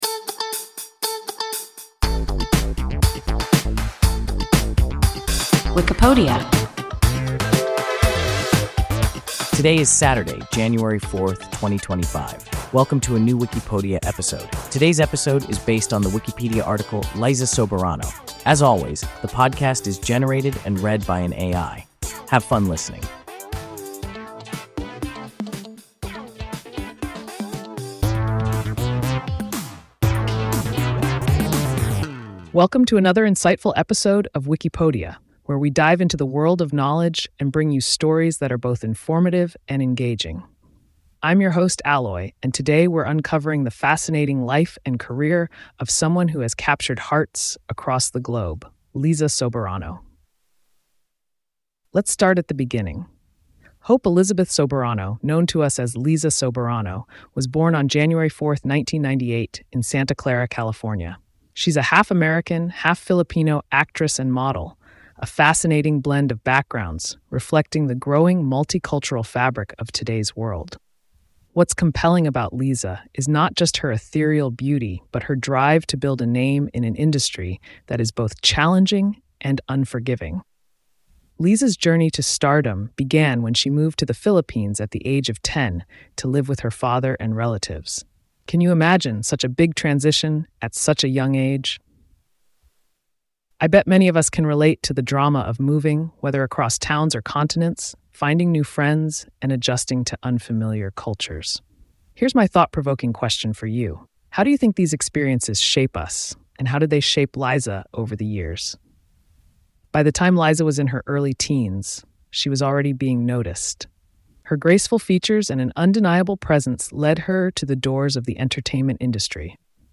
Liza Soberano – WIKIPODIA – ein KI Podcast